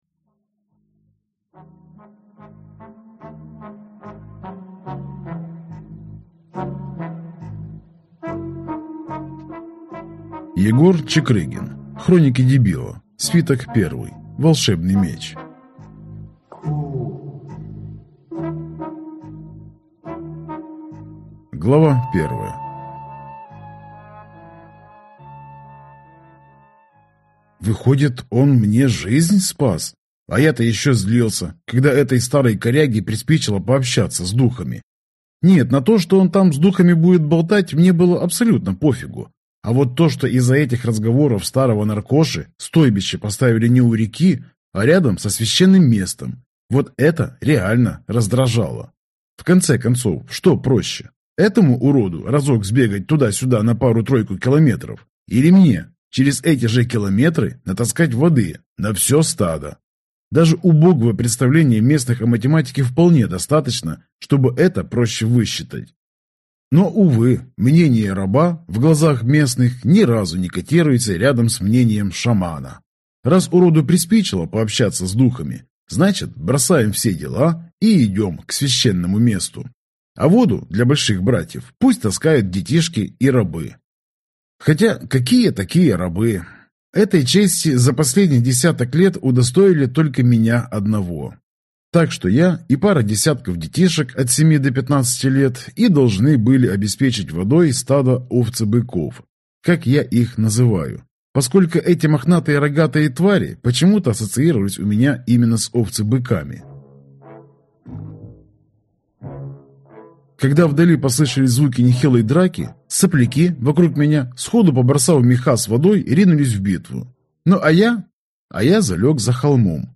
Аудиокнига Хроники Дебила. Свиток 1. Волшебный Меч | Библиотека аудиокниг